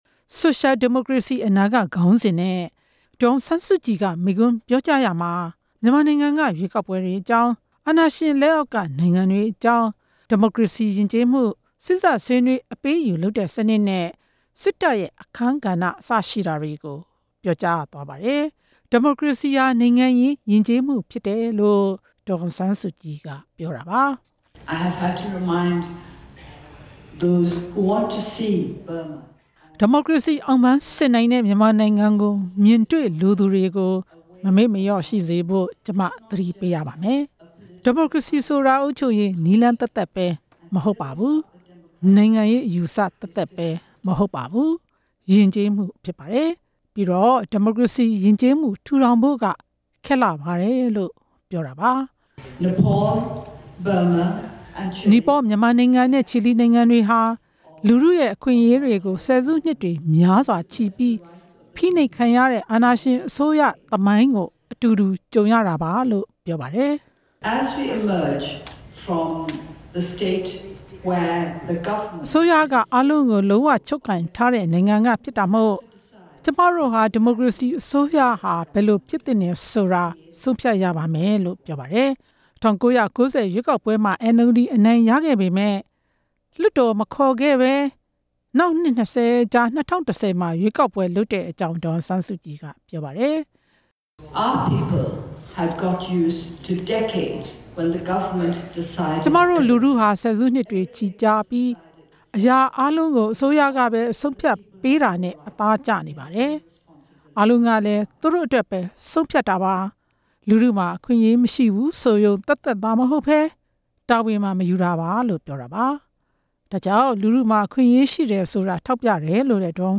နီပေါပါလီမန်မှာ မနေ့က မိန့်ခွန်းပြောကြားချိန်မှာ အဲဒီလိုပြောကြားတာပဲဖြစ်ပါတယ်။
ဒီမိုကရေစီညီလာခံမှာ ဒေါ်အောင်ဆန်းစုကြည်ပြောကြားတဲ့ မိန့်ခွန်းကောက်နုတ်ချက်ကို နားဆင်ပါ။